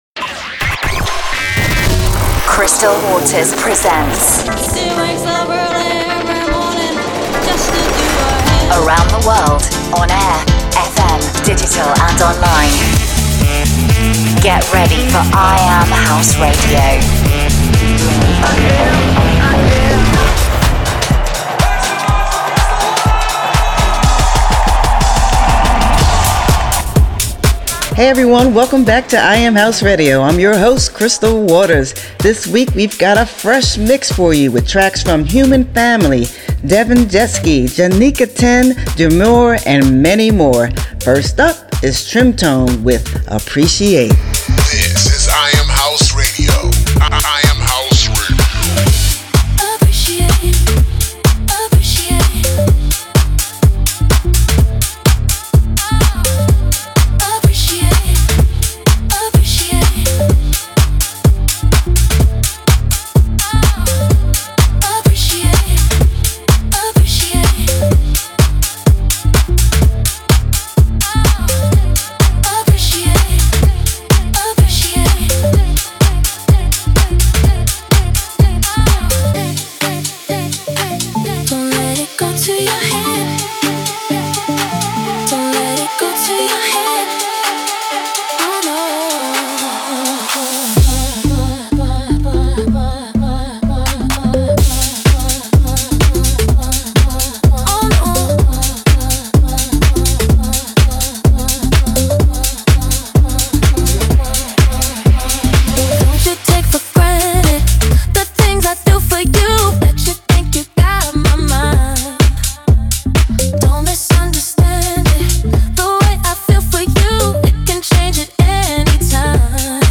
Playing the best new House Music from around the world.